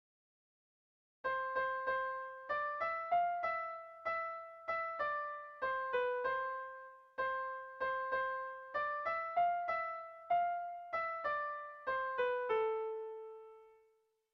Kontakizunezkoa
Lauko txikia (hg) / Bi puntuko txikia (ip)
A1A2